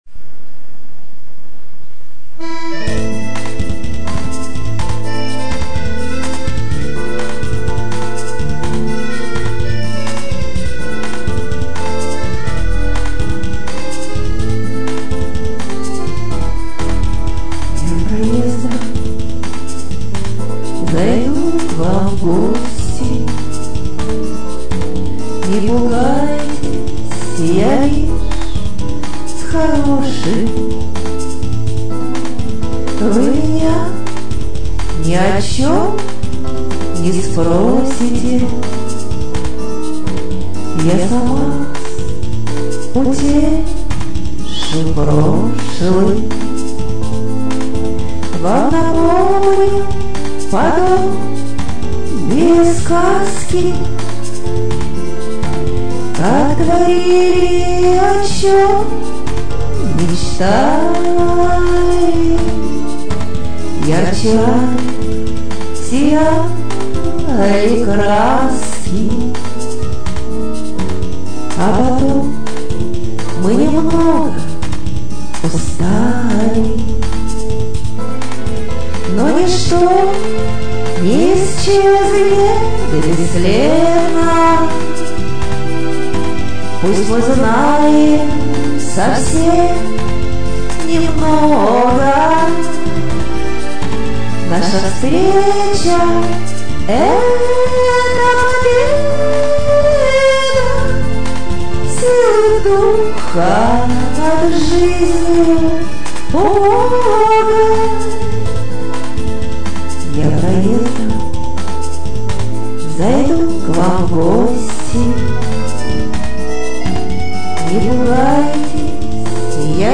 Я проездом… исп. автор
муз. из интернета